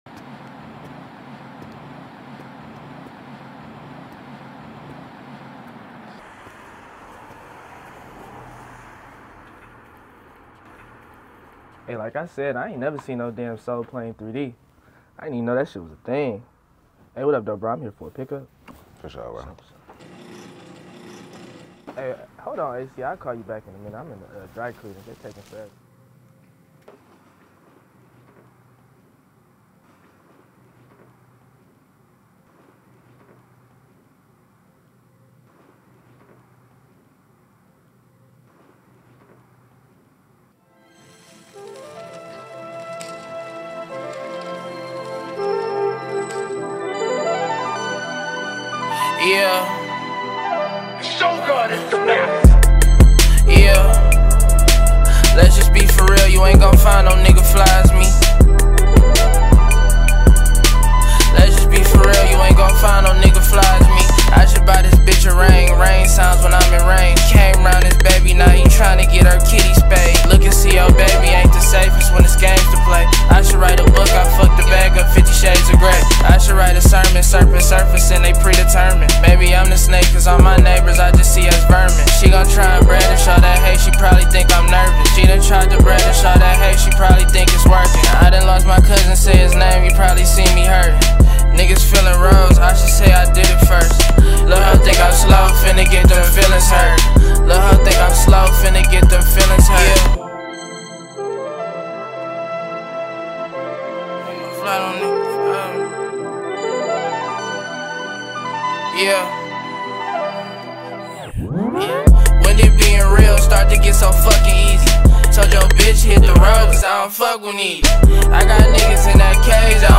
” offering smooth vocals
rich production